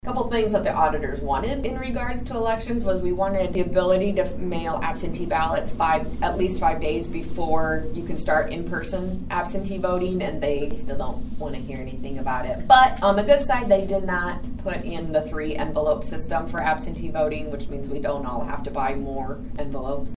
Franklin County Auditor Katy Flint told the board that the statewide auditor’s association has been lobbying for legislative changes.